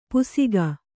♪ pusiga